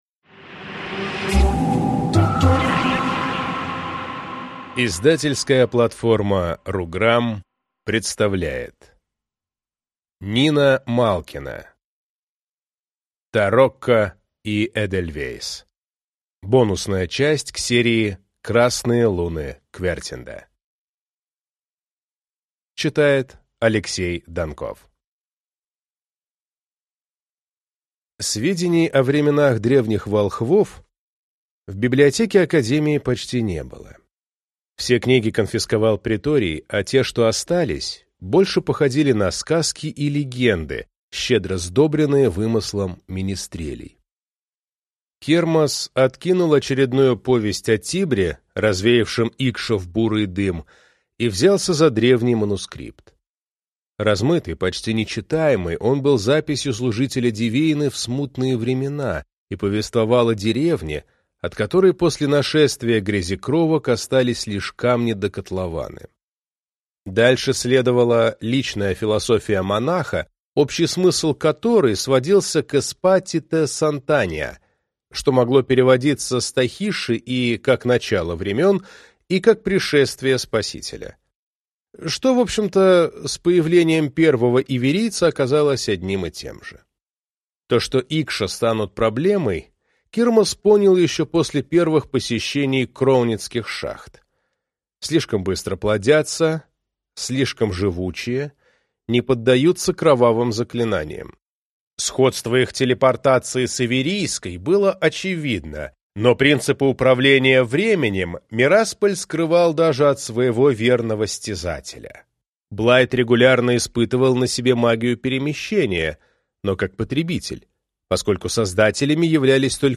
Аудиокнига Тарокко и эдельвейс | Библиотека аудиокниг